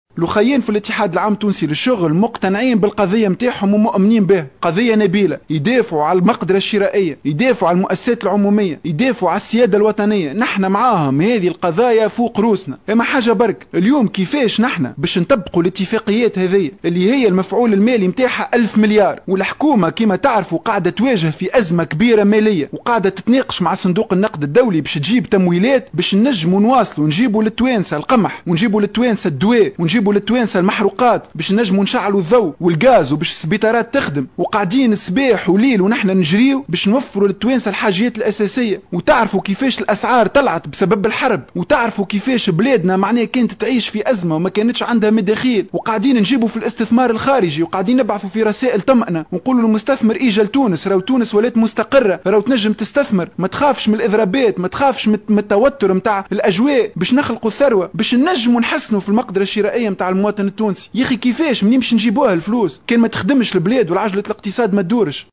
إعتبر الناطق بإسم الحكومة نصر الدين النصيبي،في حوار مع الإذاعة الوطنية، أنّ...